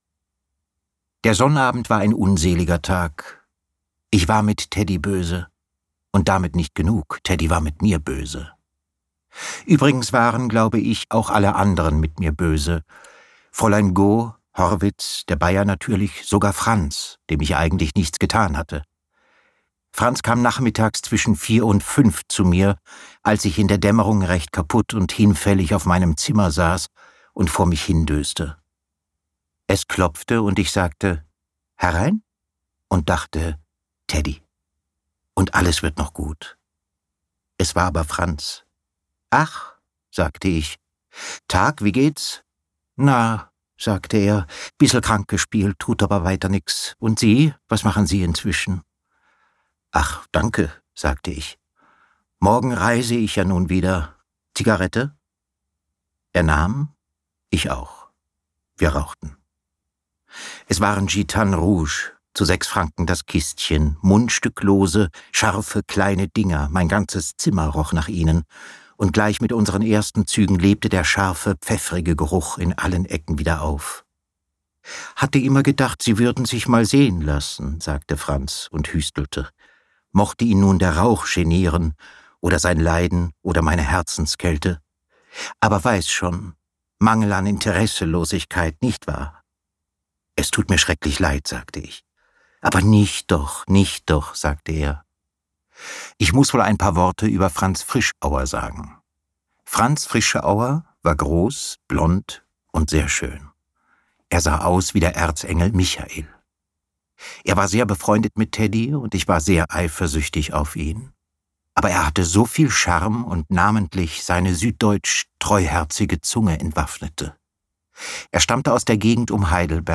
Sebastian Blomberg (Sprecher)
2025 | 3. Auflage, Ungekürzte Ausgabe